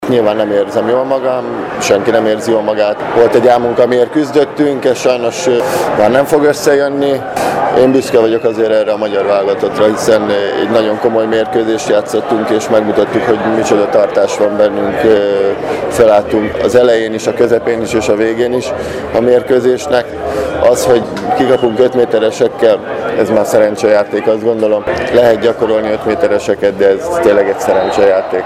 Szívós Márton is magába roskadva jött ki az újságírók elé: